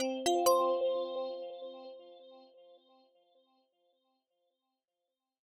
We created luxurious compositions to evoke the ever-shifting skies, with a complete sound set of immersive original music and UX sounds heards throughout the traveler's journey.
Power On